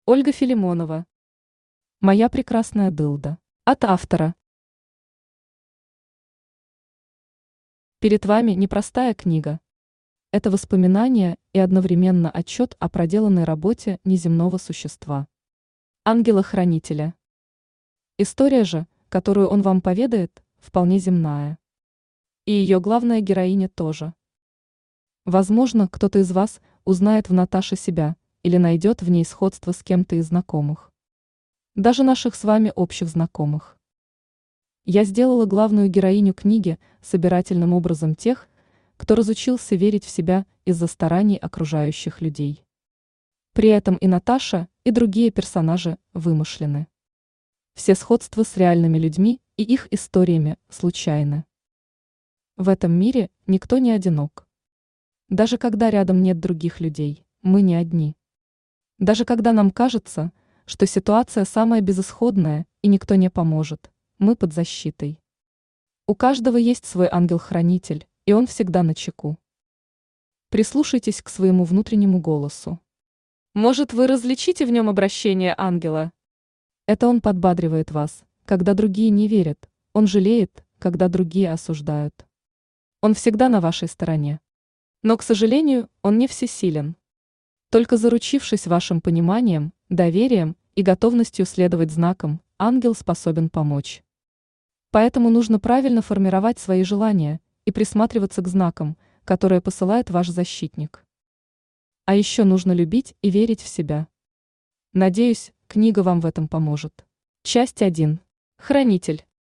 Аудиокнига Моя прекрасная Дылда | Библиотека аудиокниг
Aудиокнига Моя прекрасная Дылда Автор Ольга Филимонова Читает аудиокнигу Авточтец ЛитРес.